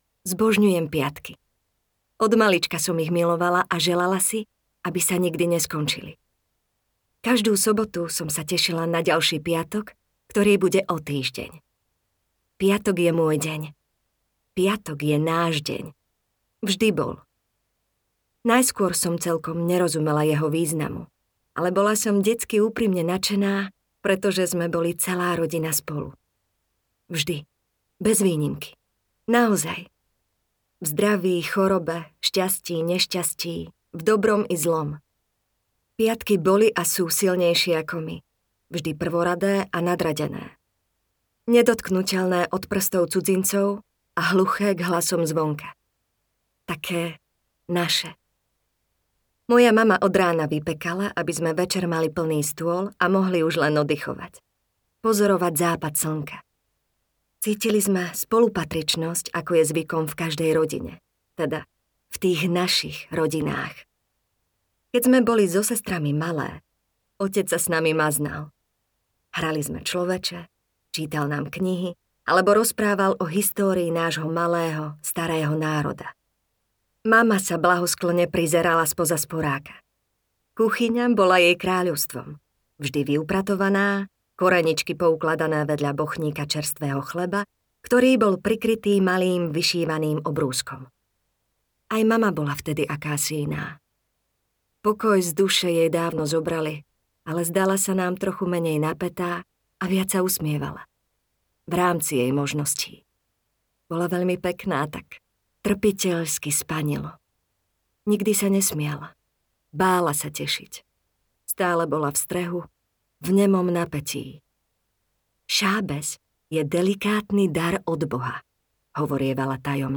Židovka audiokniha
Ukázka z knihy